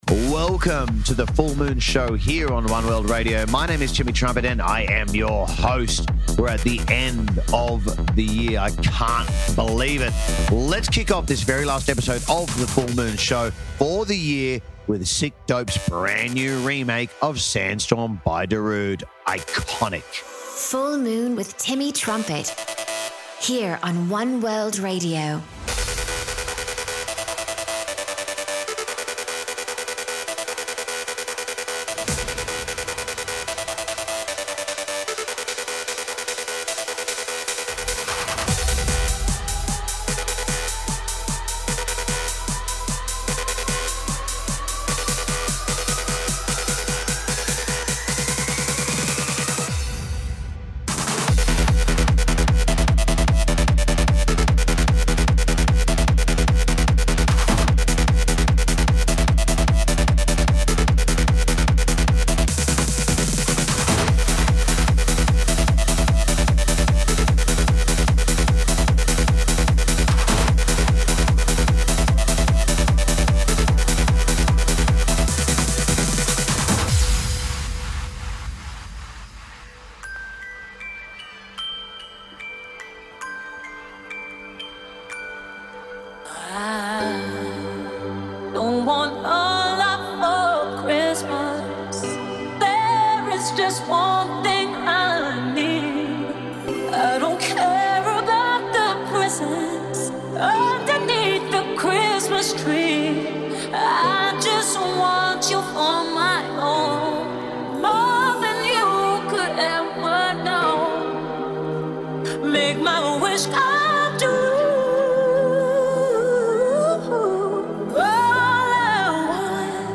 House mix